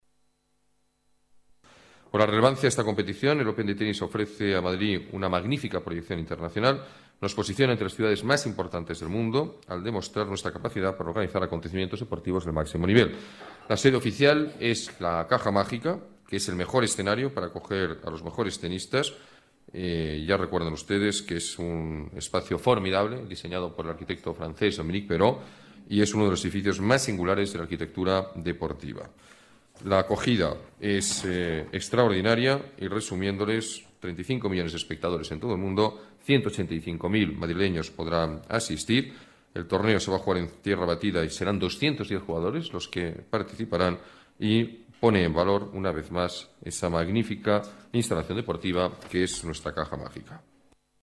Nueva ventana:Palabras del alcalde, Alberto Ruiz-Gallardón.